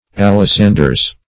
Search Result for " alisanders" : The Collaborative International Dictionary of English v.0.48: Alexanders \Al`ex*an"ders\, Alisanders \Al`i*san"ders\, n. [OE. alisaundre, OF. alissandere, fr. Alexander or Alexandria.]